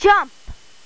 1 channel
jump.mp3